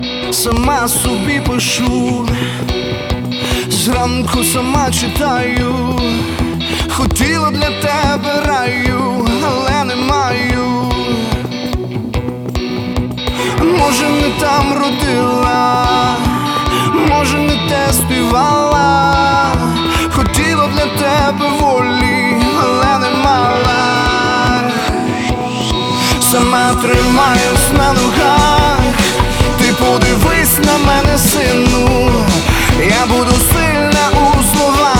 Жанр: Украинские
# Рок